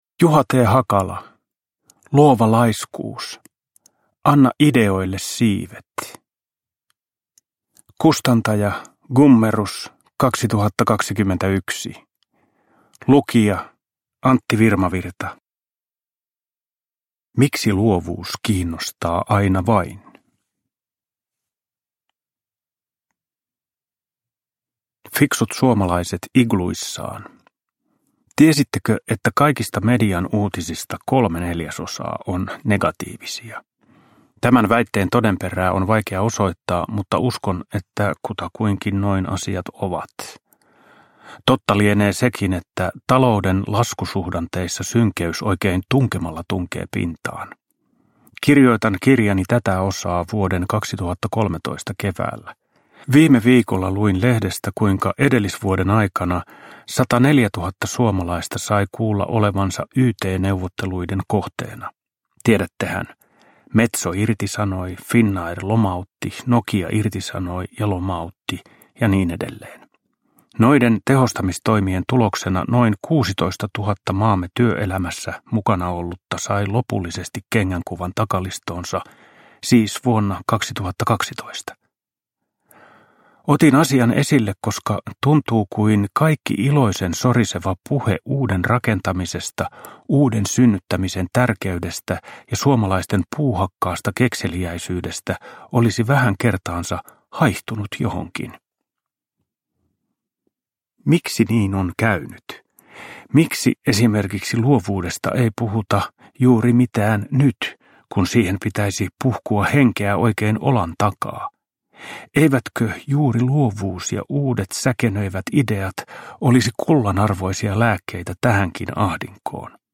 Luova laiskuus – Ljudbok – Laddas ner